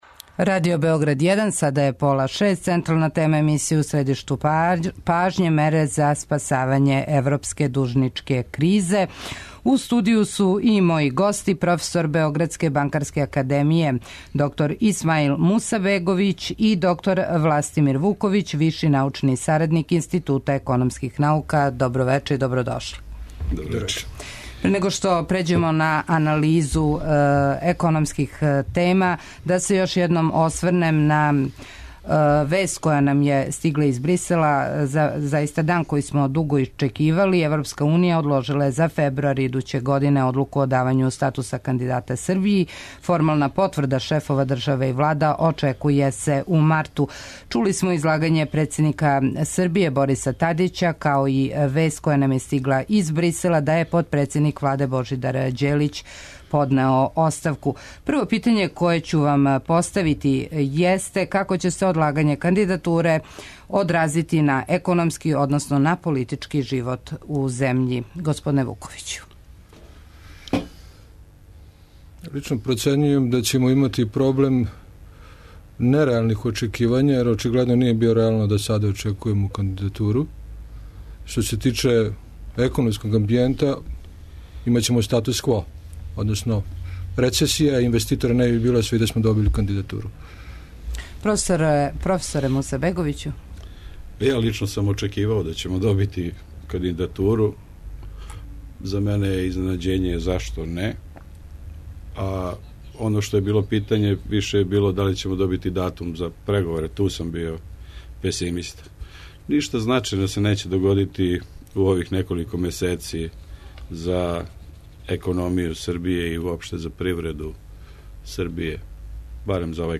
У средишту пажње – Србија и Европска Унија. Одлука о кандидатури и мере за спасавање еврозоне. Вести из Брисела коментарисаће економисти у студију